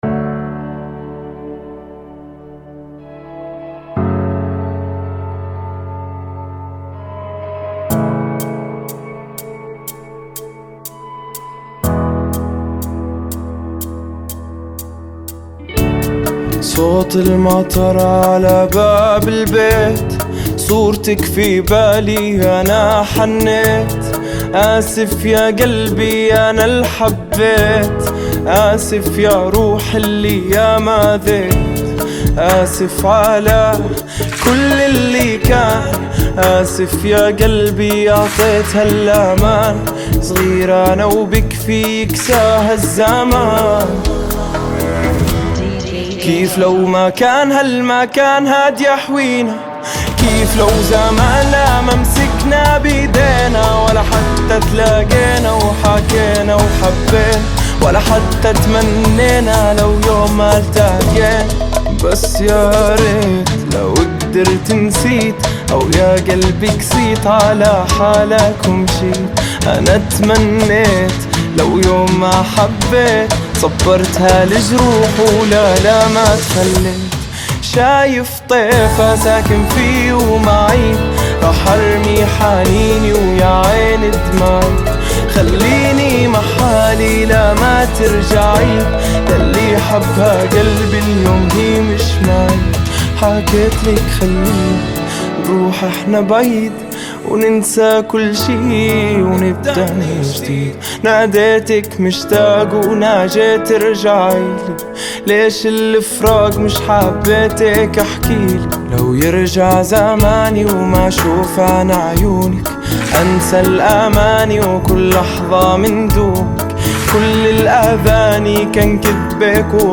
122 BPM
Genre: Bachata Remix